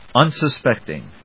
un・sus・pect・ing /`ʌnsəspéktɪŋ/